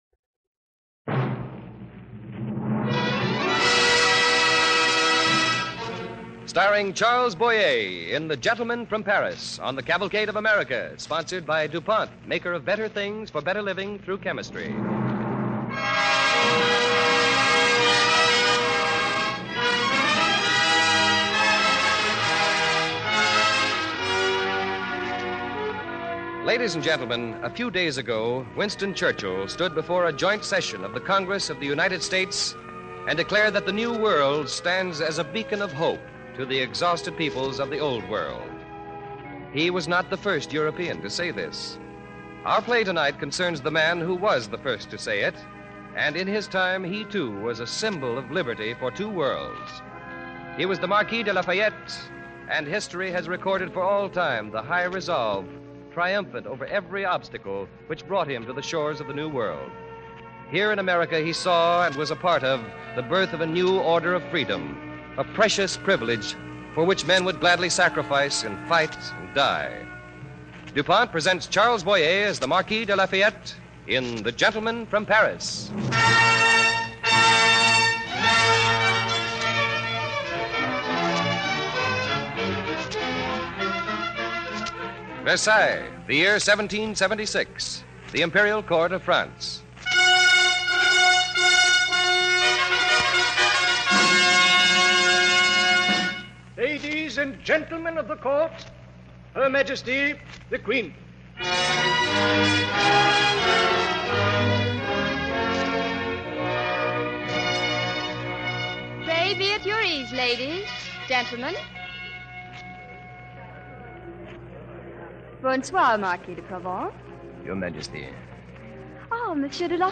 Cavalcade of America Radio Program
The Gentleman from Paris, starring Charles Boyer